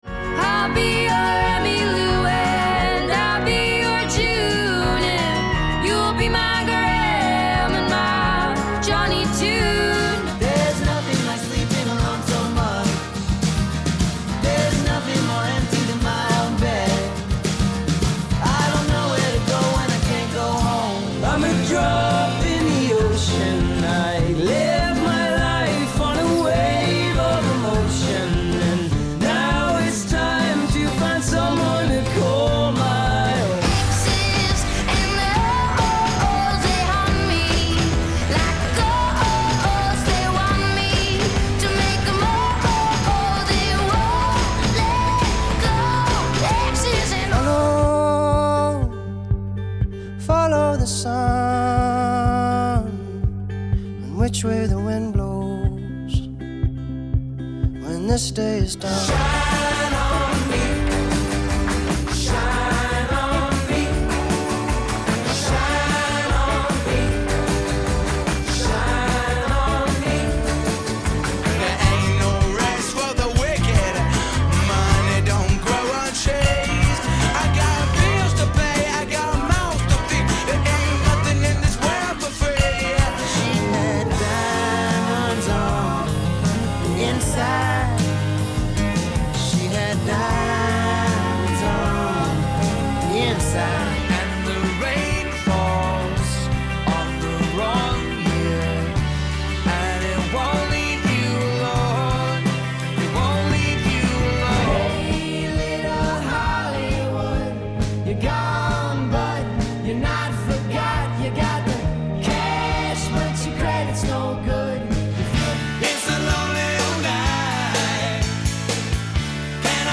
Mixed Tempo